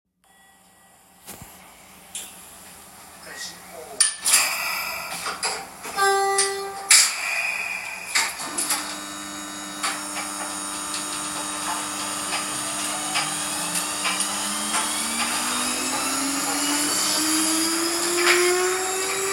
⑭＜オプション＞ニューシャトル1050系サウンドコレクションCD【走行音、動作音、ドア開閉音など】イベント限定特別価格で先行販売「事前申し込み」2,000円税込（現在は発売しておりません／但し、今後異なる金額で発売する可能性がございます）
案内軌条式鉄道とは、走行路面上の側壁にある案内軌条に案内輪をあてて、ゴムタイヤで走行する交通機関。